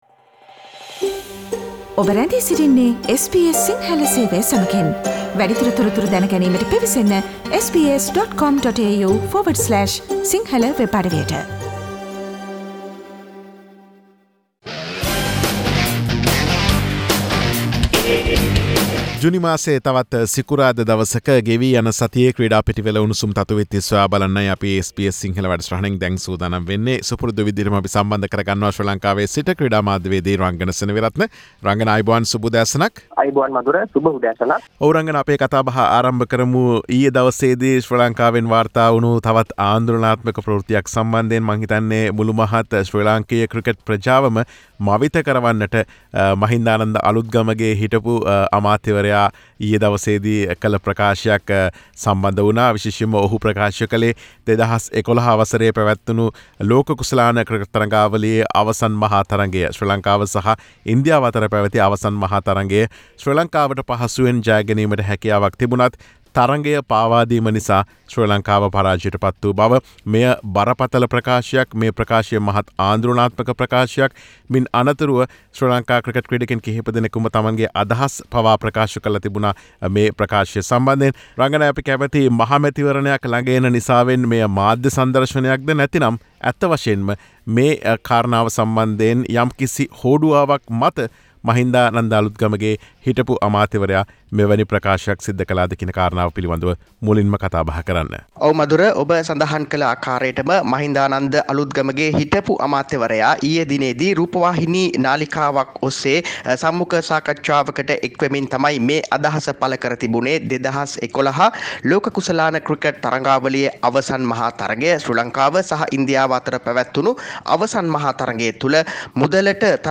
SBS Sinhalese Sports Wrap